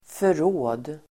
Uttal: [för'å:d]